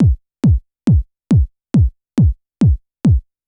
BD        -R.wav